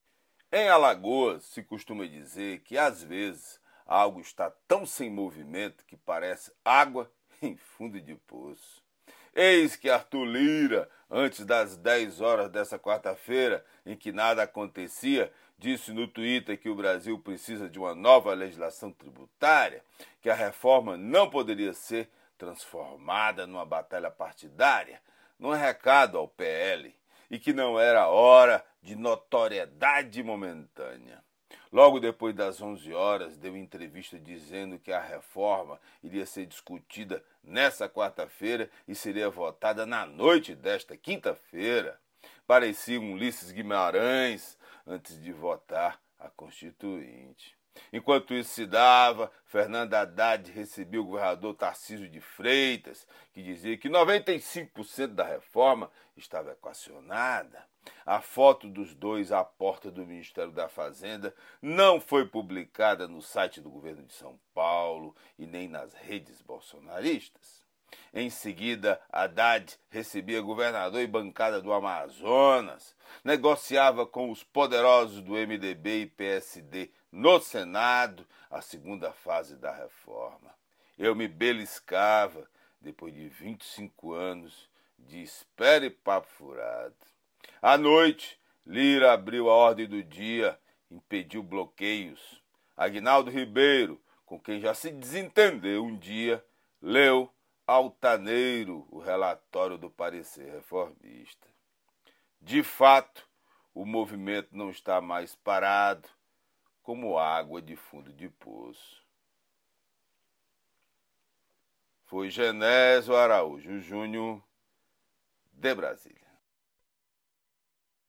COMENTÁRIO DIRETO DE BRASIL